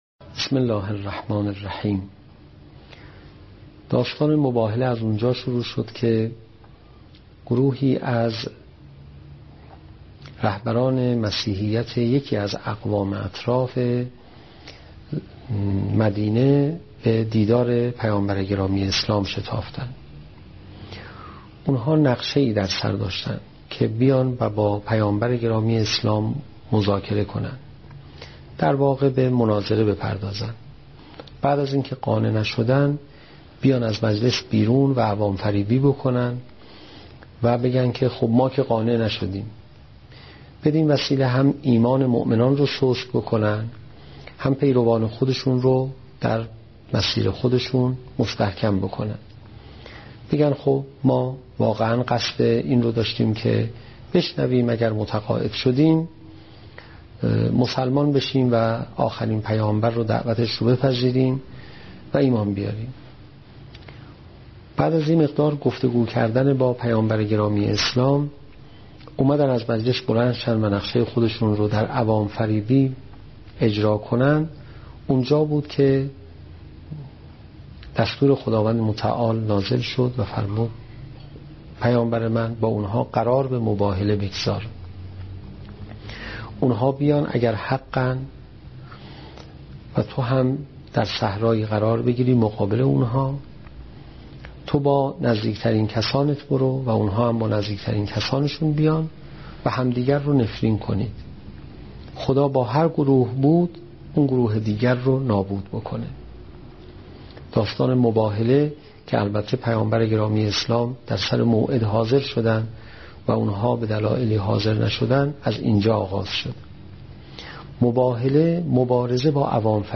سخنرانی روز مباهله